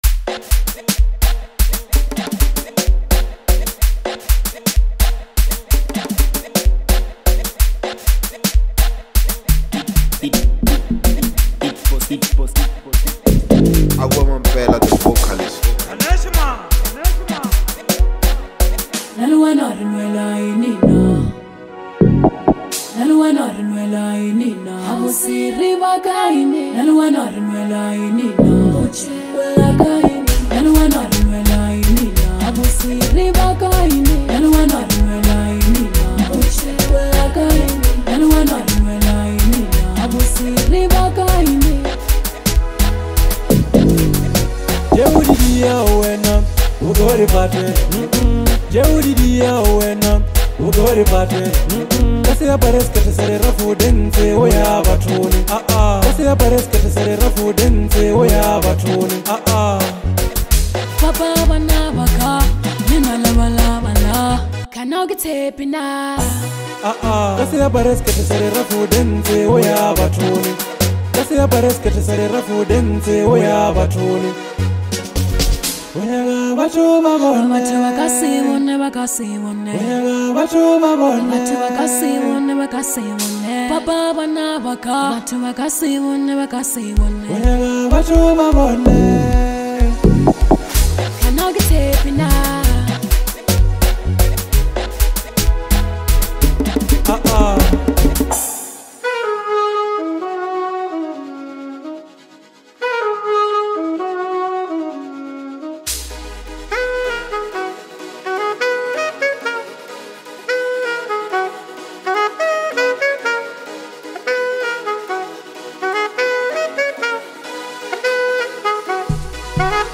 Don’t miss out on this dancefloor banger!